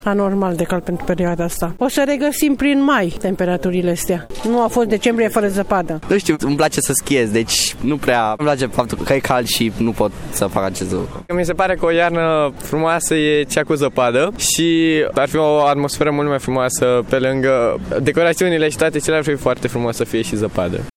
VOXURI-TANTI-SI-TINERI-.mp3